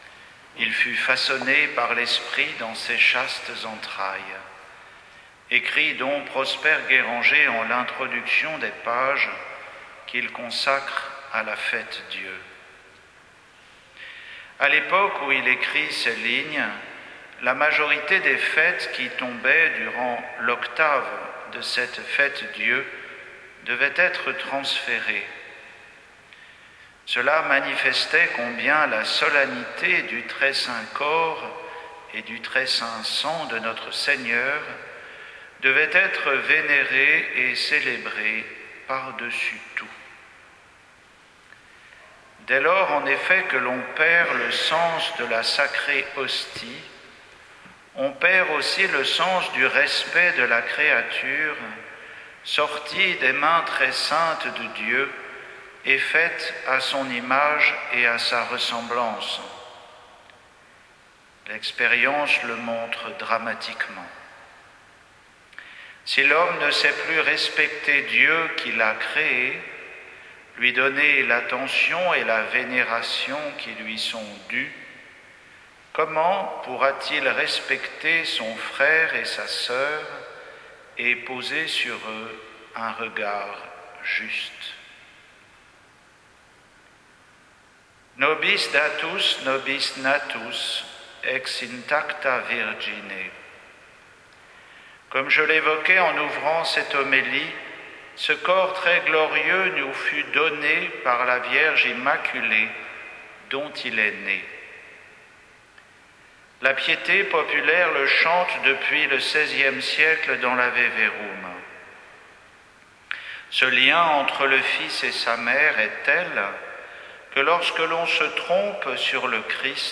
Homélie pour la solennité du Corps et du Sang du Christ, jeudi 8 juin 2023